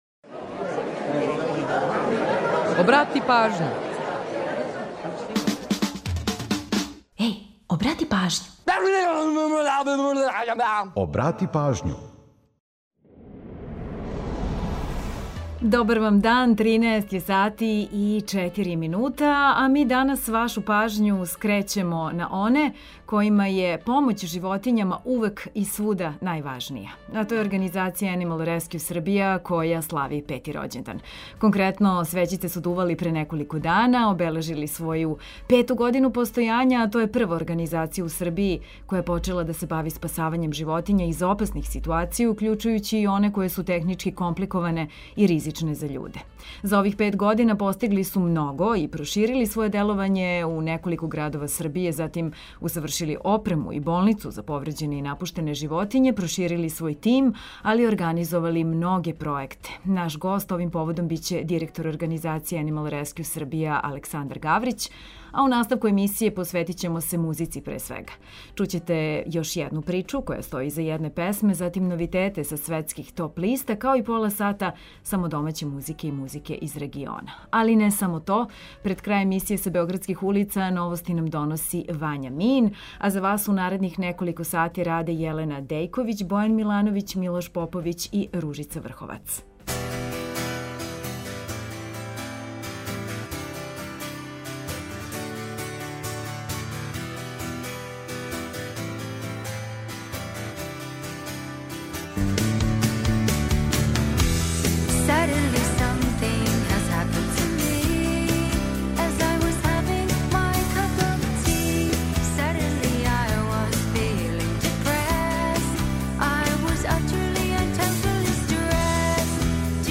У наставку емисије, посвећујемо се музици, пре свега. Чућете причу која стоји иза једне песме, новитете са светских топ листа као и пола сата само домаће музике и музике из региона.